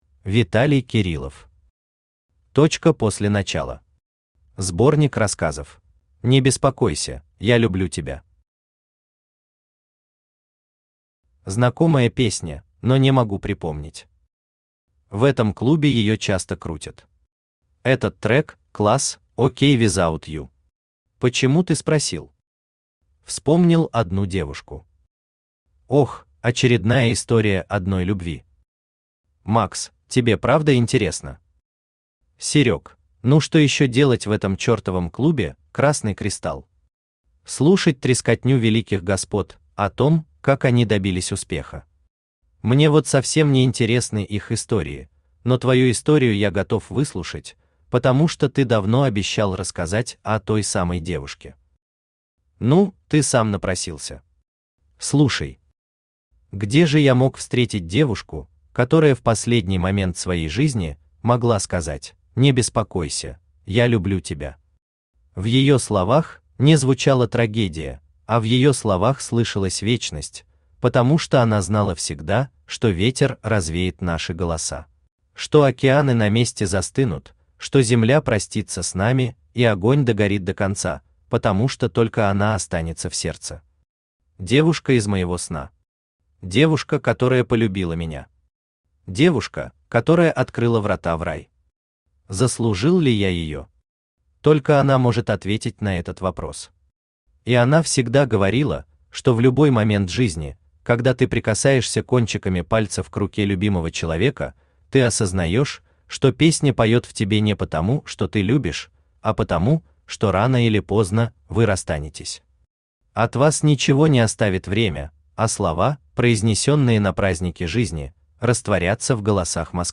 Аудиокнига Точка после начала. Сборник рассказов | Библиотека аудиокниг
Сборник рассказов Автор Виталий Александрович Кириллов Читает аудиокнигу Авточтец ЛитРес.